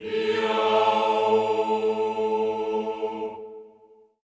HEE-AH  A2-R.wav